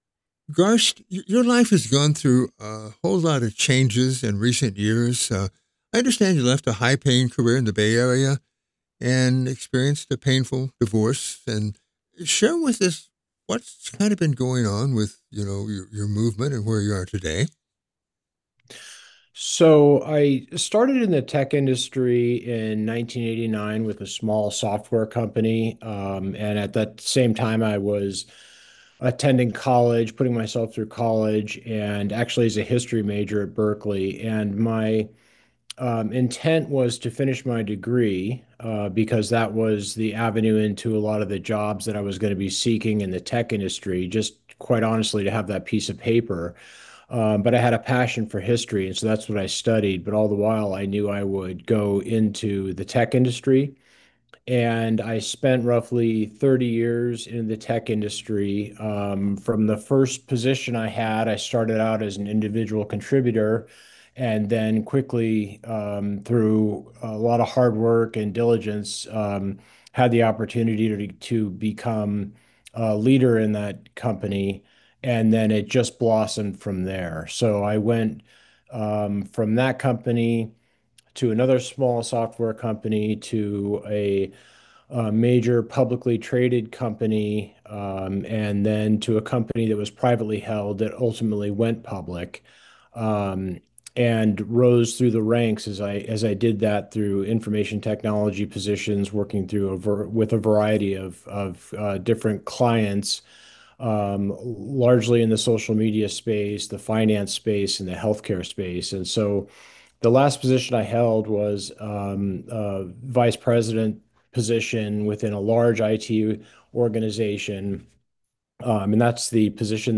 (Complete interview podcast)